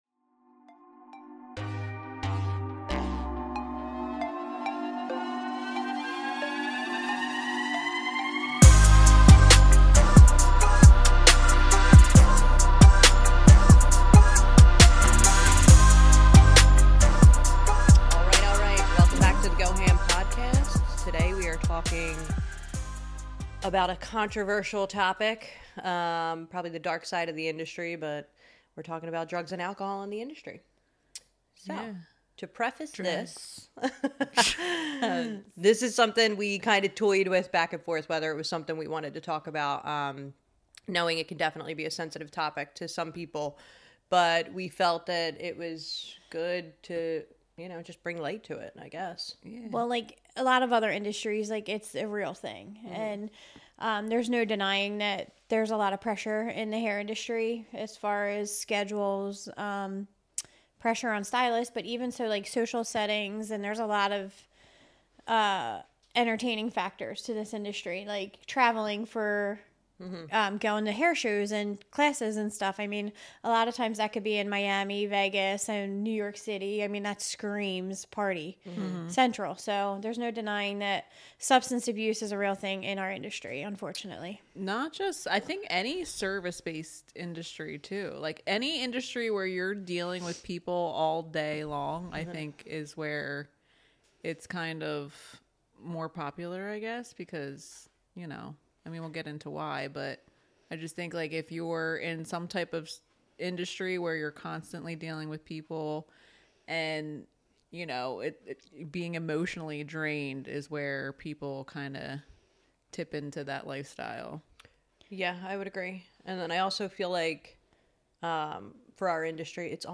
This is an honest conversation about managing rising substance abuse and it's effects on mental health.